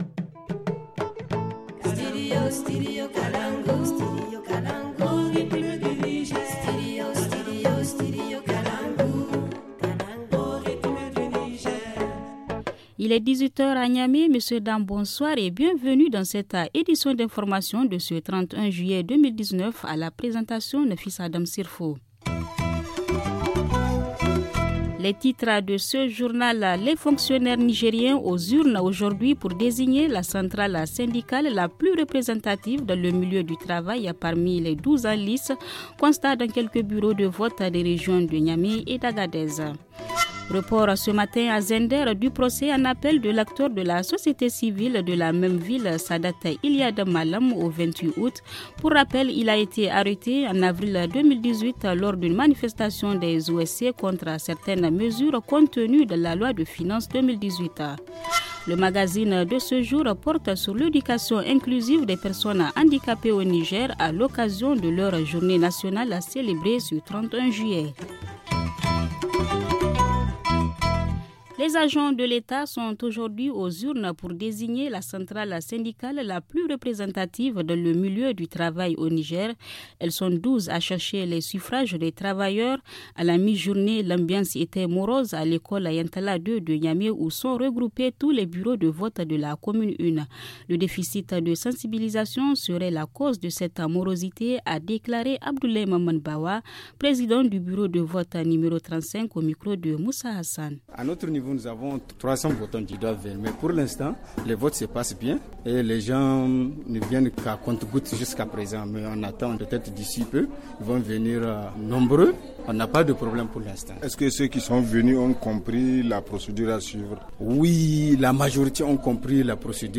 Le journal du 31 juillet 2019 - Studio Kalangou - Au rythme du Niger